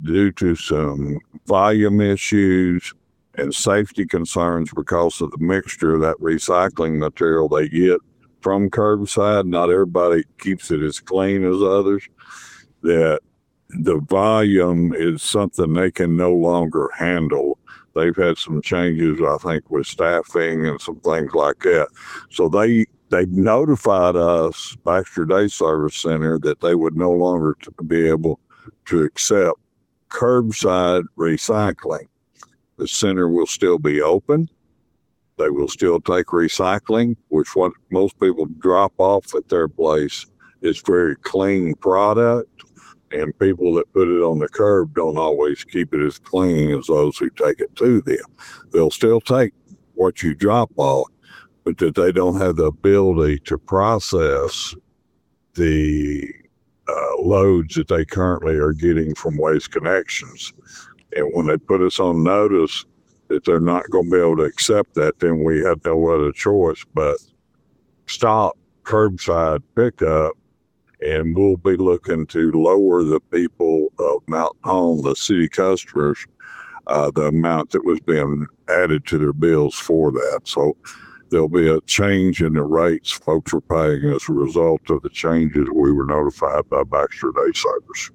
The mayor says the recycling center is having to stop accepting the items picked up each week by Waste Connections for several reasons.